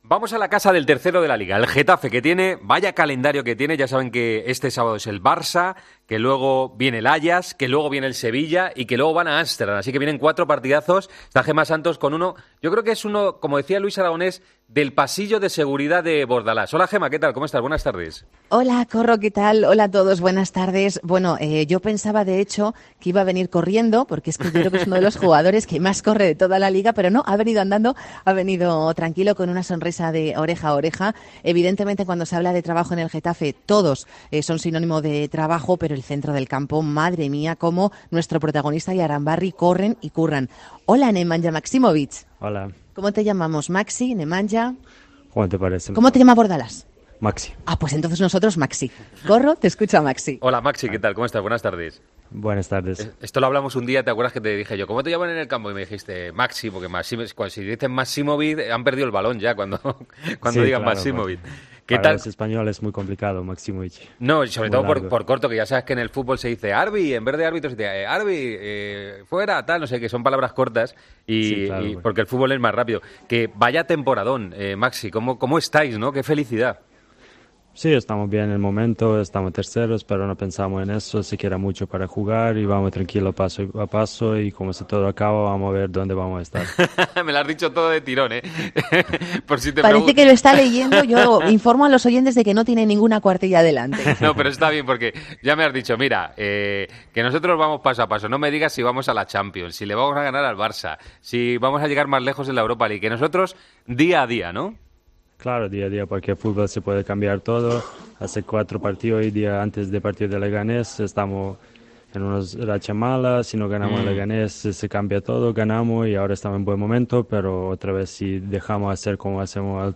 Hablamos con el centrocampista serbio del Getafe.